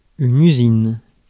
Ääntäminen
Ääntäminen France: IPA: [y.zin]